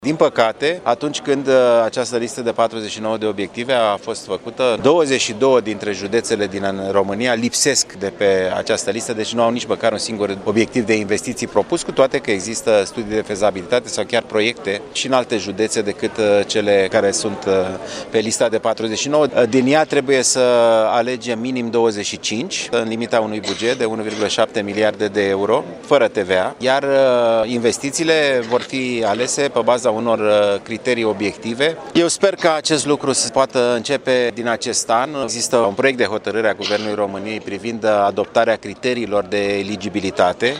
Ministrul Sănătăţii Alexandru Rafila a explicat astăzi, la Bistriţa, că în PNRR este prevăzută o listă de 49 unităţi medicale eligibile pentru echipare sau dotare, dar numai 25 dintre ele vor fi admise pentru finanţare: